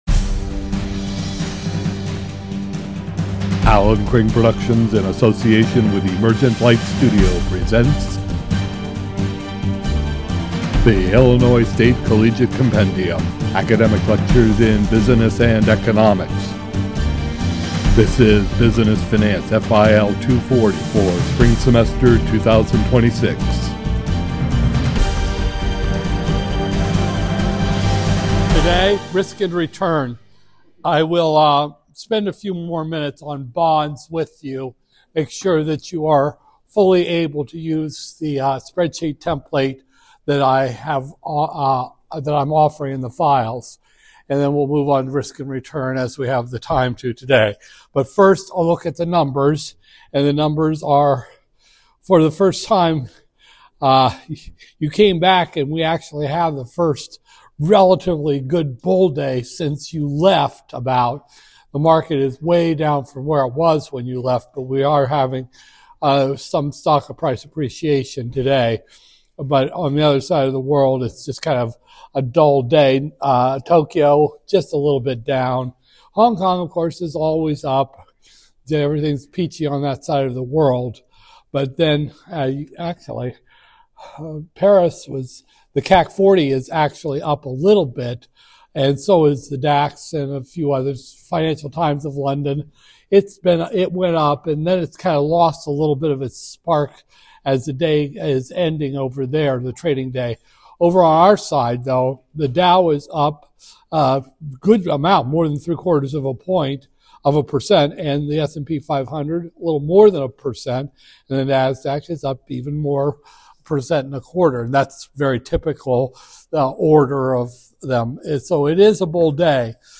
Business Finance, FIL 240-001, Spring 2026, Lecture 15